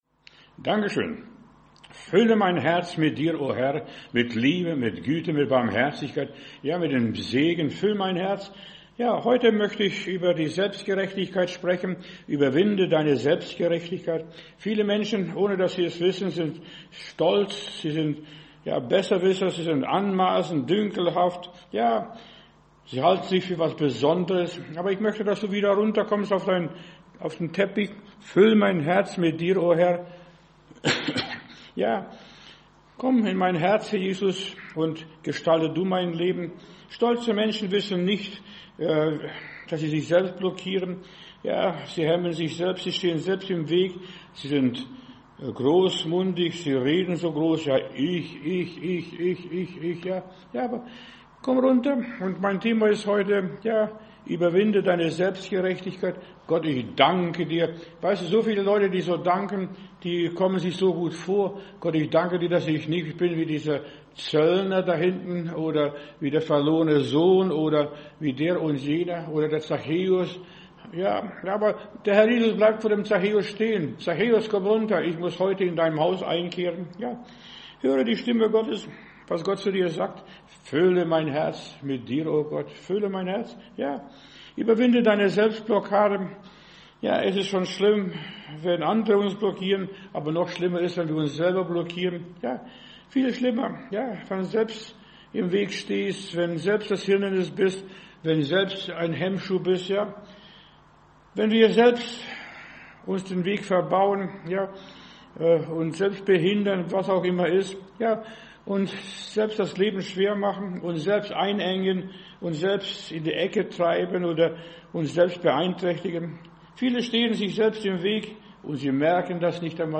Predigt herunterladen: Audio 2025-10-29 Überwinde deine Selbstgerechtigkeit Video Überwinde deine Selbstgerechtigkeit